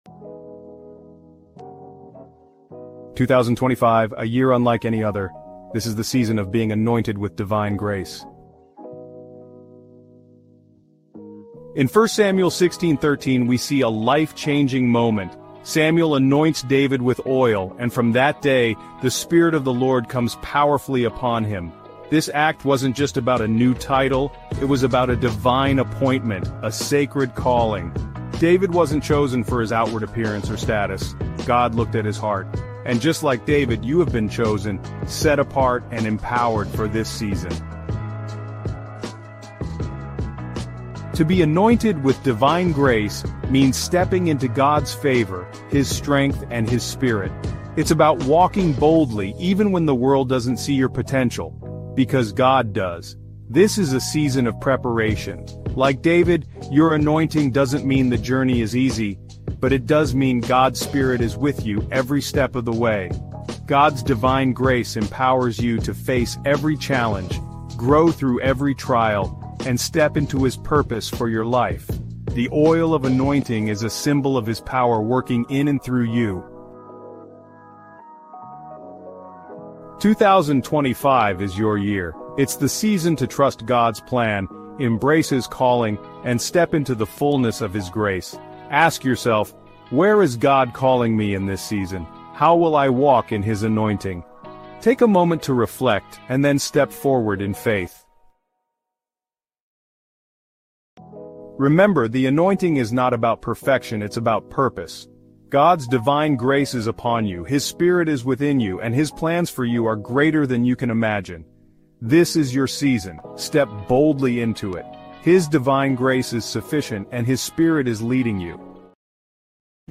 1 live-recording 4/24/2024 6:18:07 AM